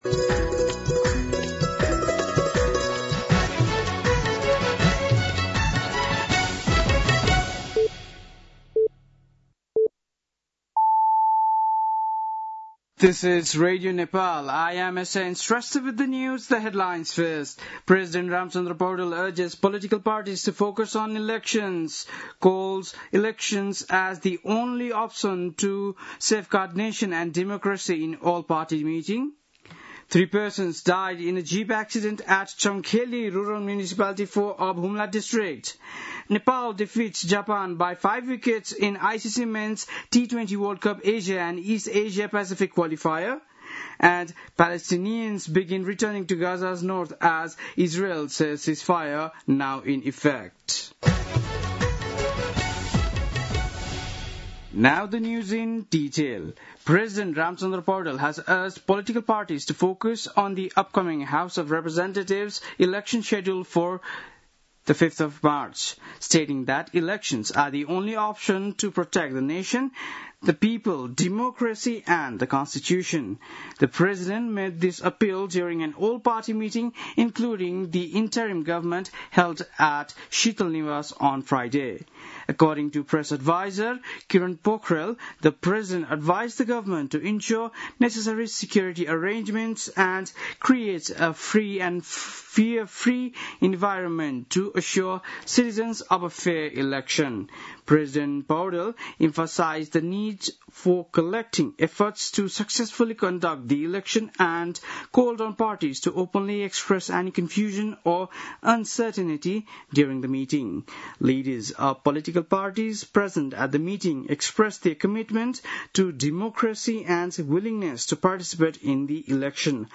बेलुकी ८ बजेको अङ्ग्रेजी समाचार : २४ असोज , २०८२